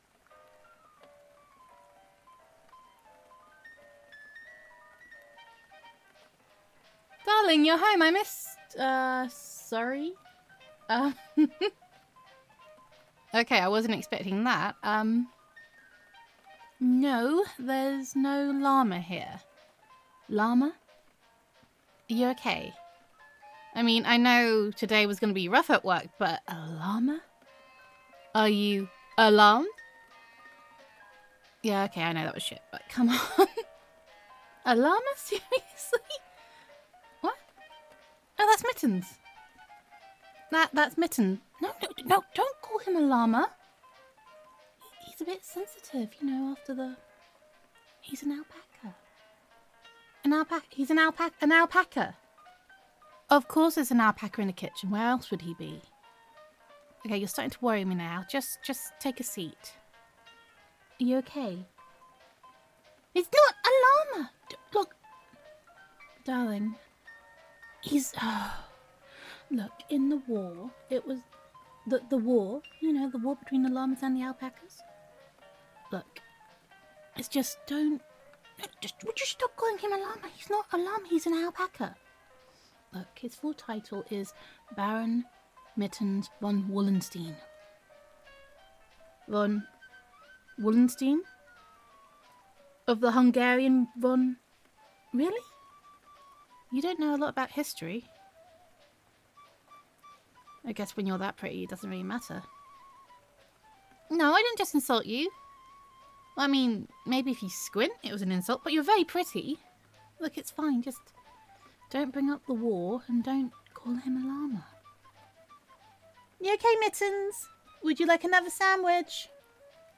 [F4A] Mittens the Emotional Support Alpaca [Fat Furry Battering Ram][When Oreos Attack][Don’t Call Him a Llama][He Is Just a Little Bit Posh][Cucumber Sandwiches][Utterly Ridiculous][Gender Neutral][A Day in the Life of Honey]